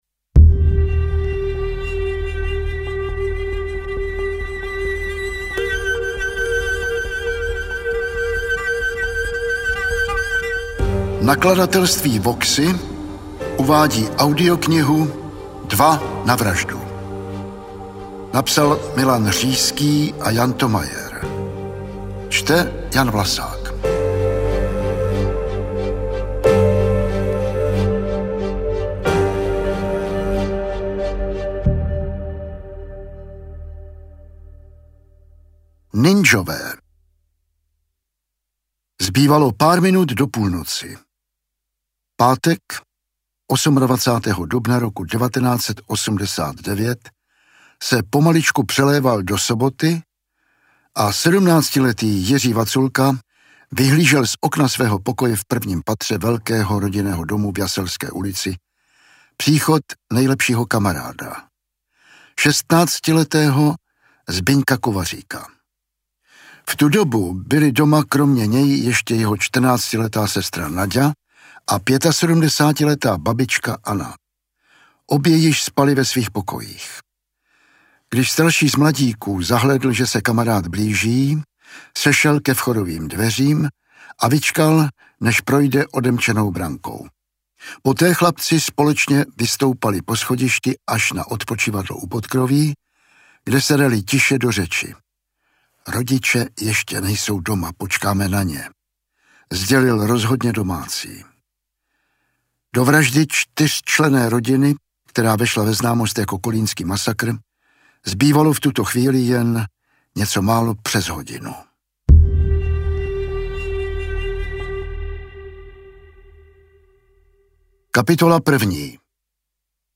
Audiobook
Read: Jan Vlasák